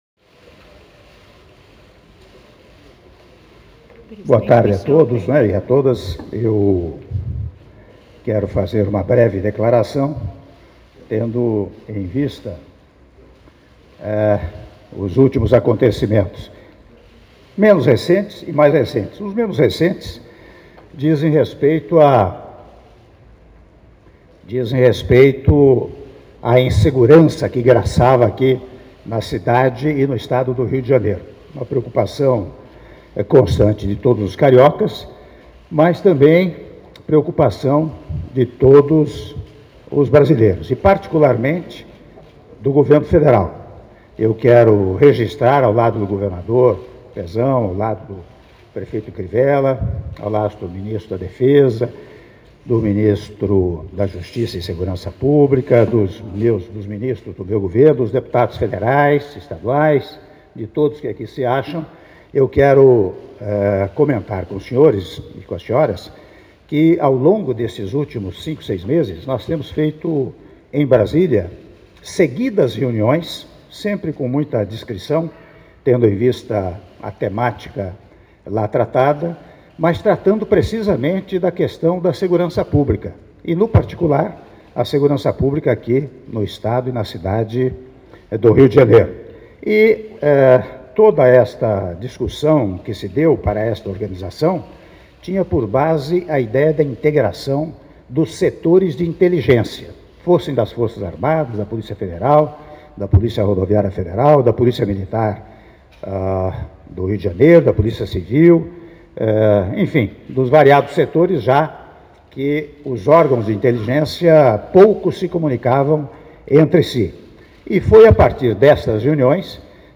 Áudio da Declaração à Imprensa do Presidente da República, Michel Temer - (06min16s) - Rio de Janeiro/RJ